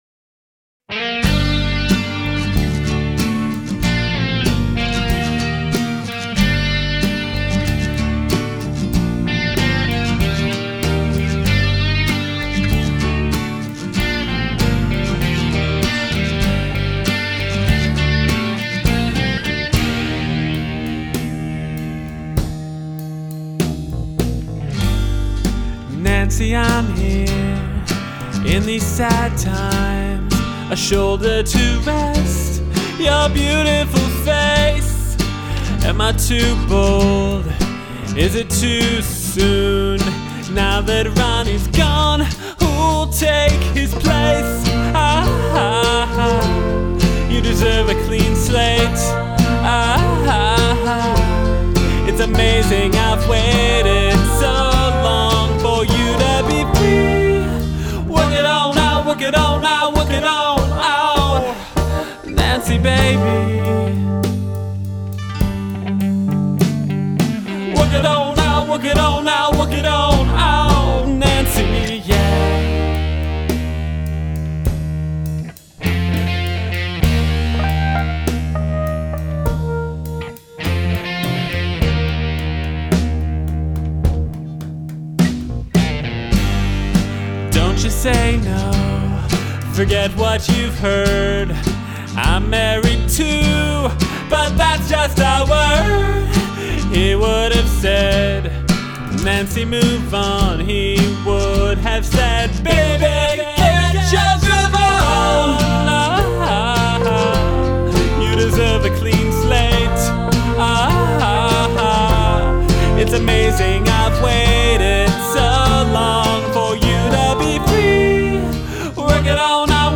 backup vocals